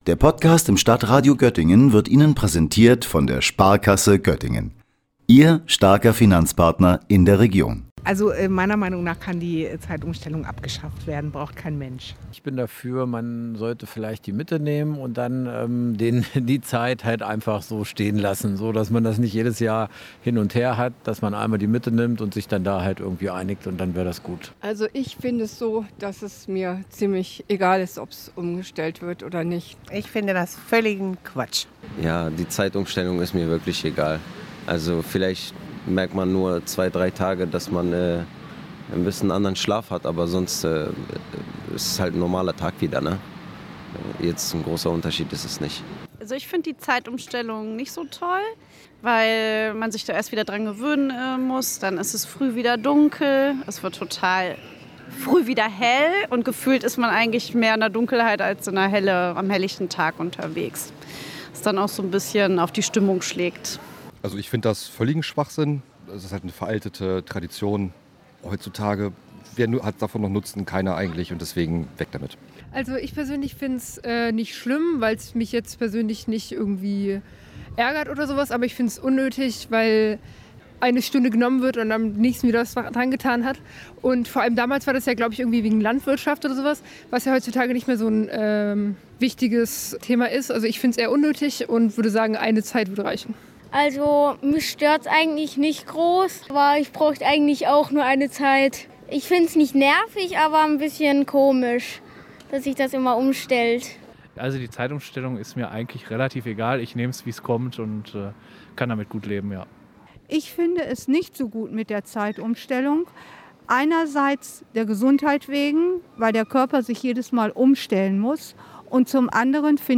Sendung: Umfragen Redaktion